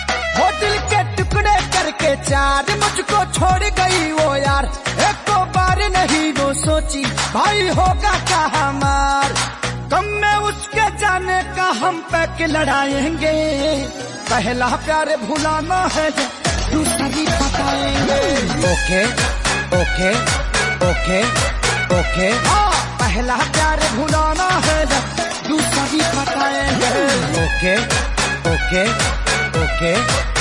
Category: Bhojpuri Ringtones